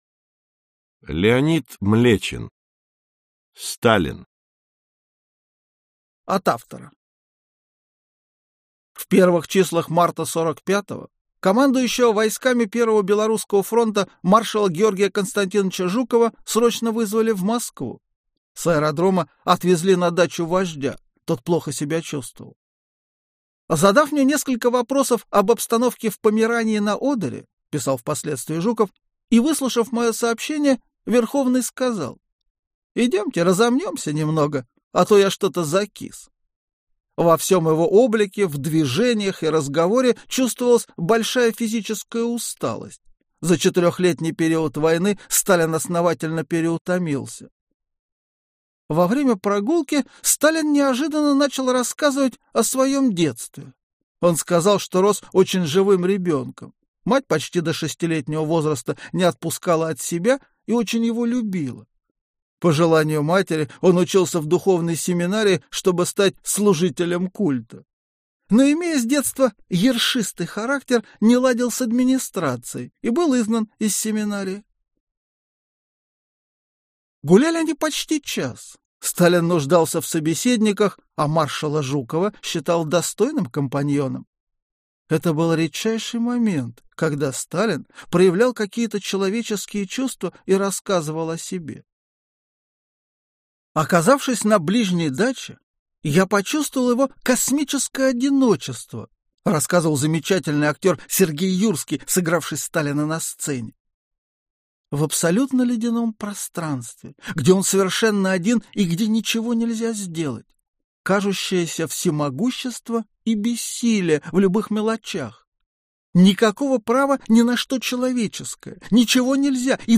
Аудиокнига СТАЛИН | Библиотека аудиокниг